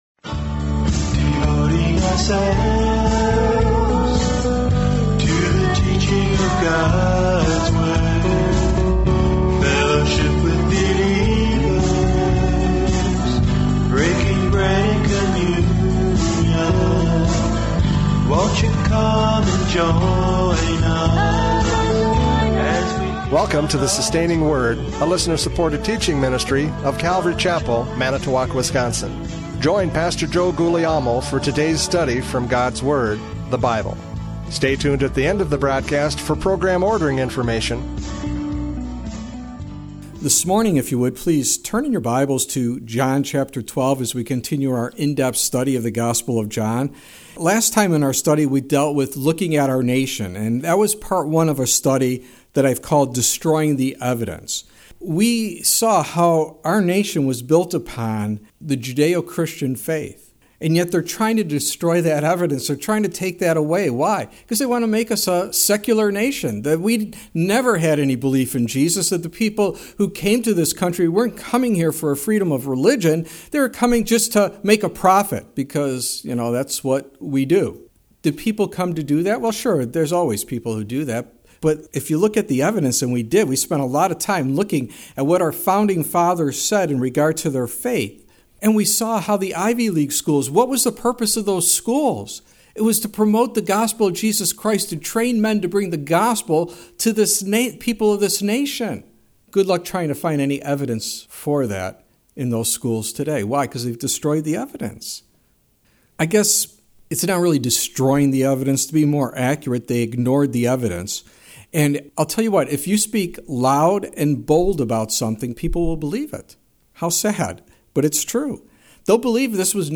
John 12:9-11 Service Type: Radio Programs « John 12:9-11 Destroying the Evidence!